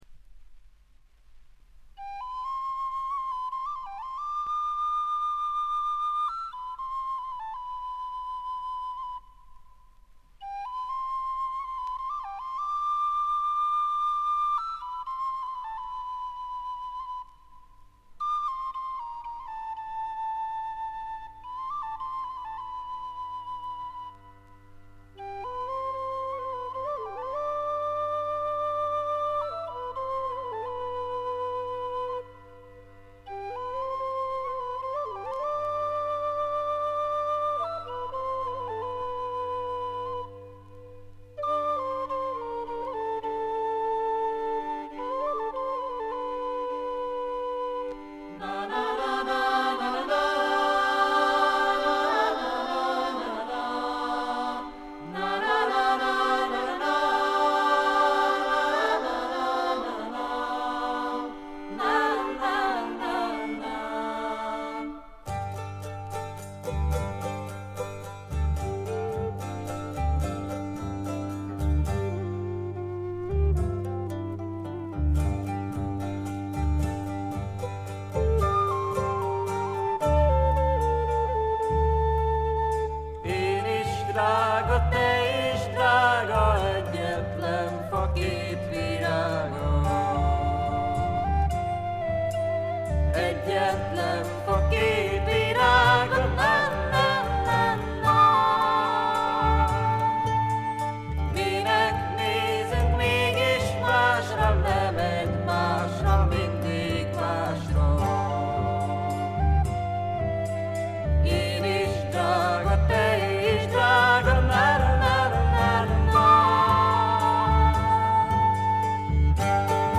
Vocals, Tambura, Violin, Gadulka, Zither [Hungarian]
Vocals, Violin, Flute, Chalumeau